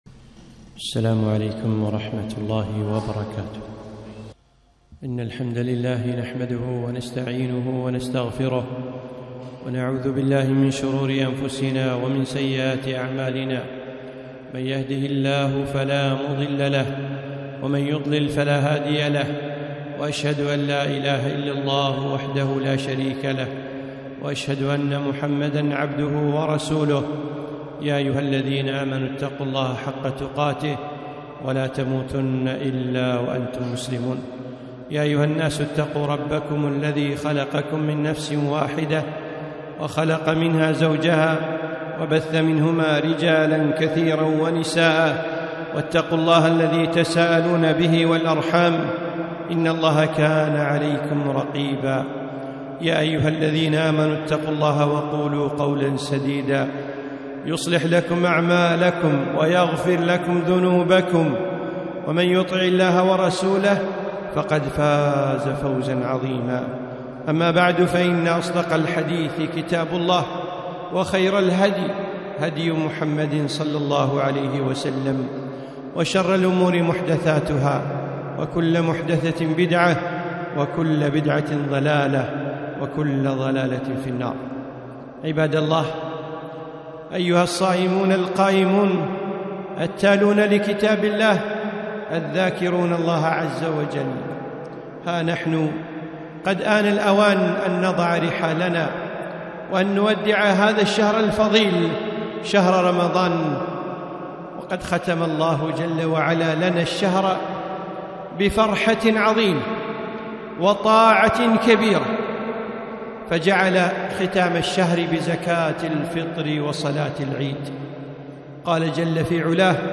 خطبة - صلاة العيد وزكاة الفطر